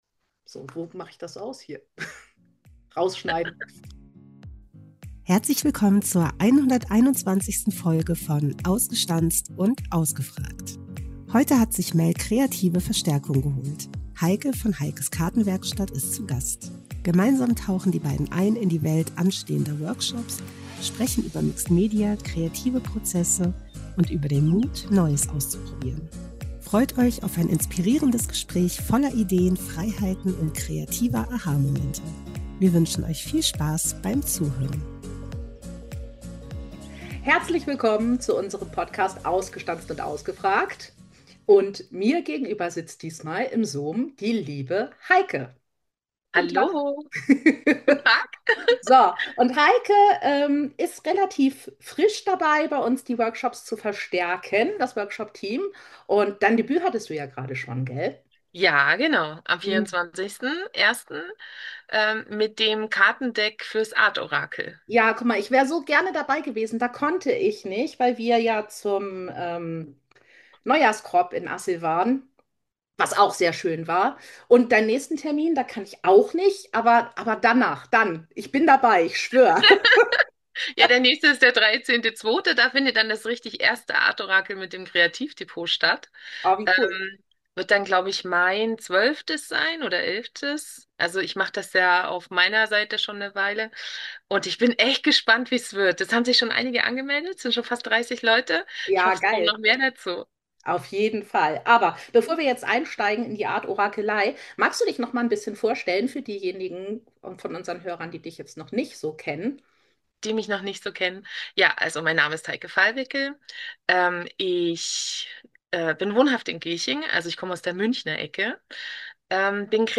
Gemeinsam tauchen die Beiden ein in die Welt anstehender Workshops, sprechen über Mixed Media, kreative Prozesse – und über den Mut, Neues auszuprobieren. Freut euch auf ein inspirierendes Gespräch voller Ideen, Freiheiten und kreativer Aha-Momente.